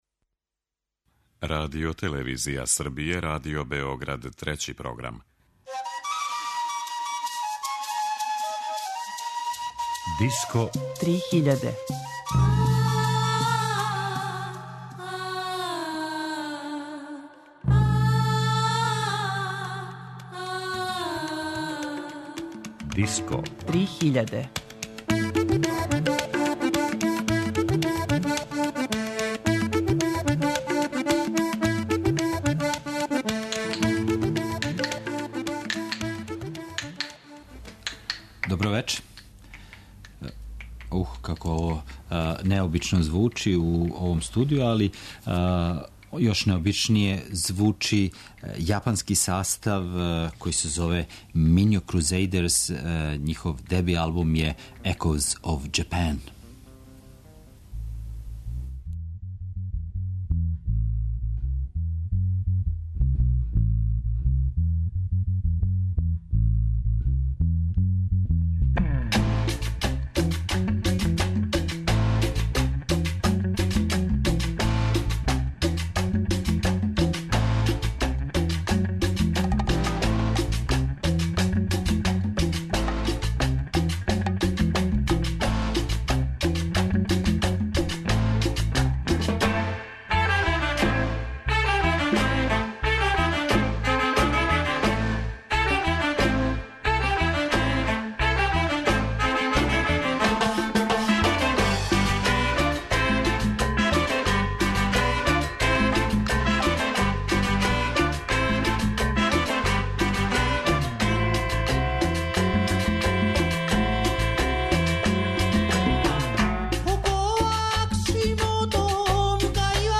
Музика са свих страна света